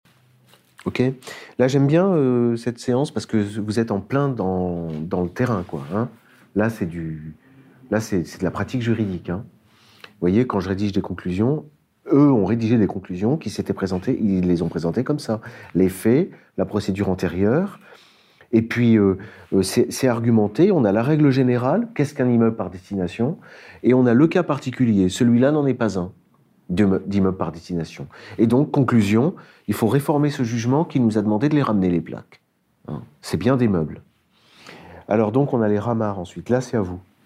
Le séminaire « les plaques de cheminée » dure une heure, c’est le live d’un cours de droit que j’ai délivré dans le cadre des Formations d’Egalité et Réconciliation.